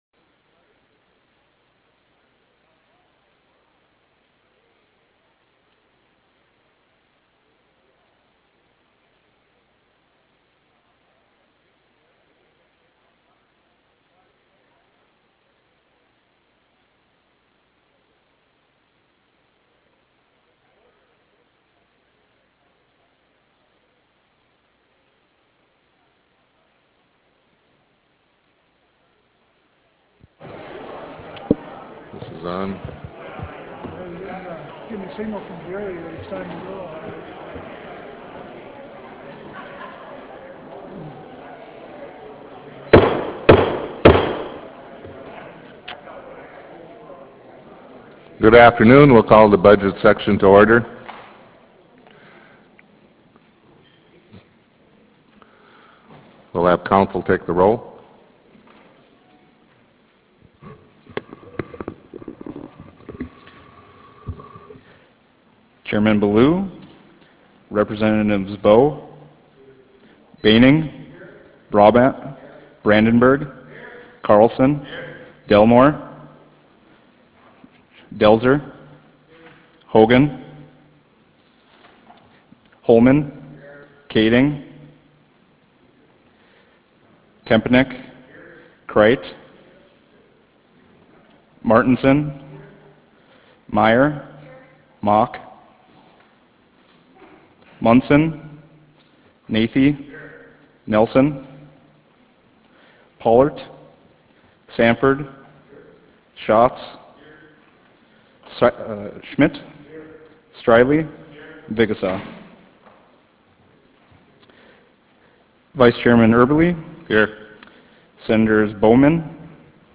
Senate Chamber State Capitol Bismarck, ND United States